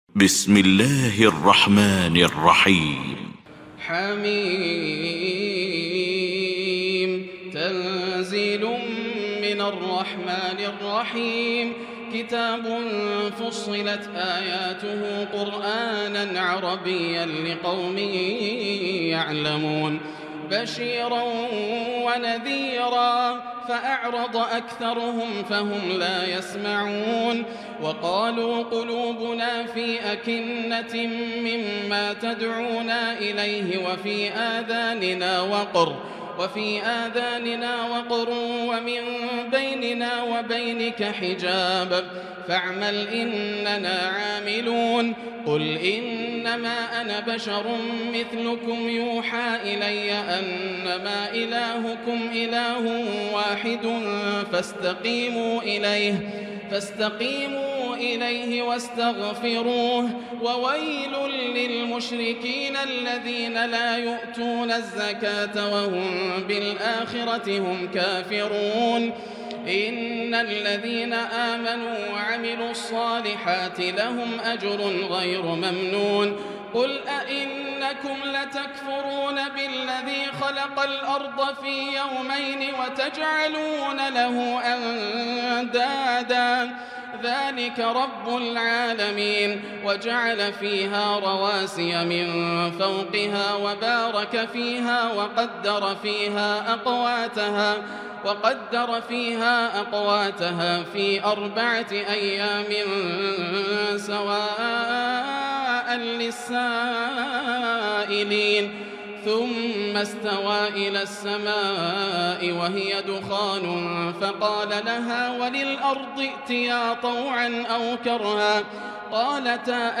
المكان: المسجد الحرام الشيخ: معالي الشيخ أ.د. بندر بليلة معالي الشيخ أ.د. بندر بليلة فضيلة الشيخ ياسر الدوسري فصلت